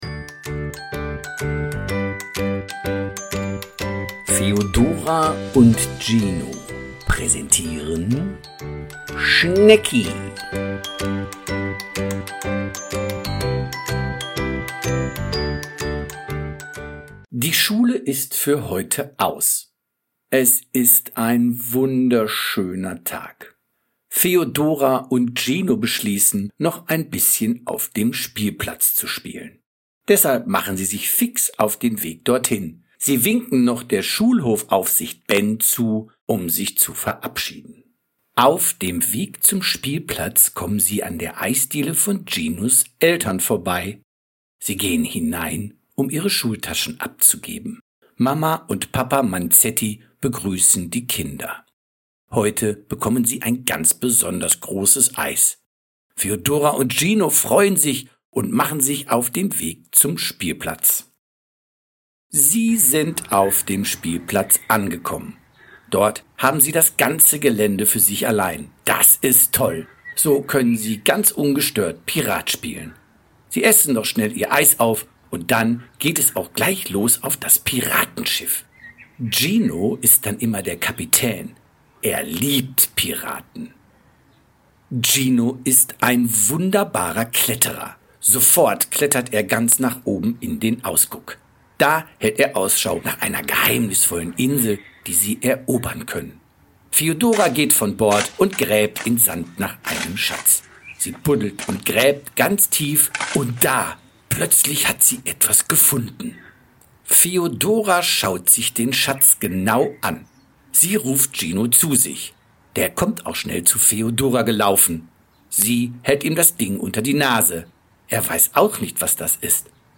Schnecki as a radio play – With Feodora + Gino on a journey full of compassion and imagination
•  Language development & listening skills: The calm, clear narrative style supports language development